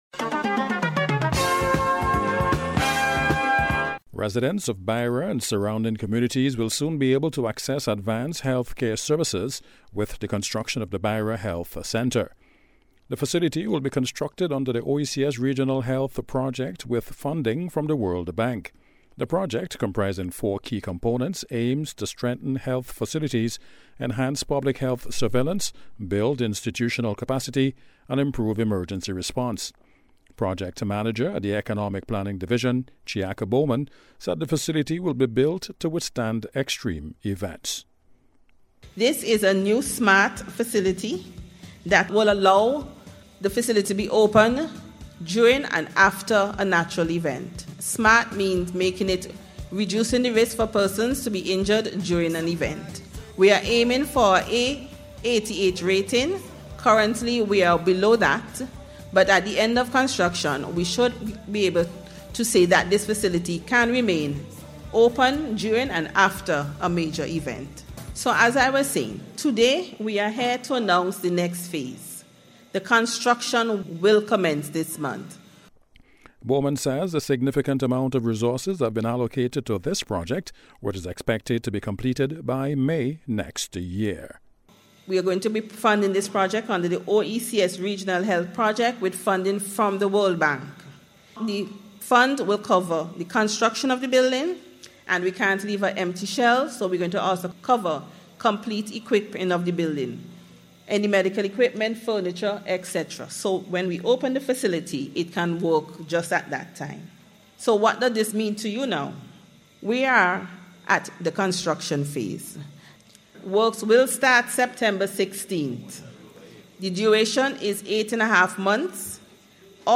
NBC’s Special Report- Tuesday 2nd Sepetember,2025